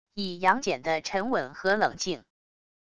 以杨戬的沉稳和冷静wav音频